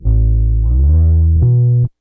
BASS 7.wav